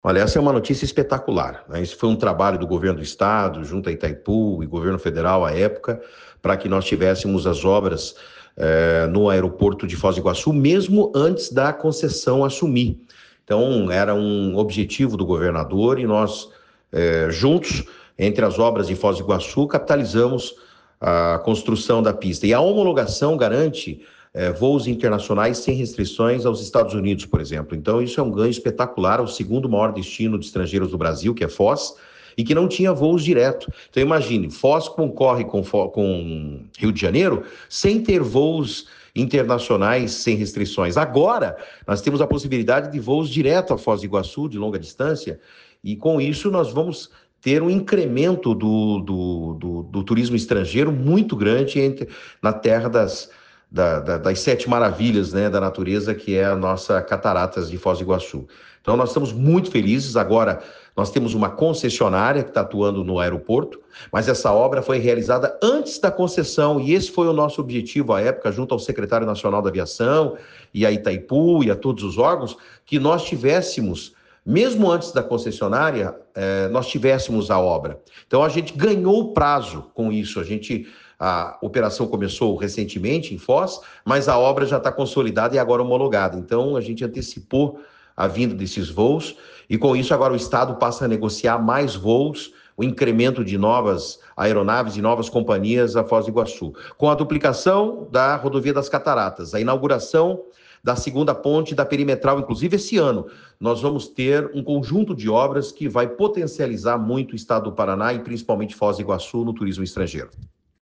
Essa ampliação permite a recepção de novos voos, além de aumentar a capacidade operacional do aeroporto, como destacou o secretário de Infraestrutura e Logística, Sandro Alex.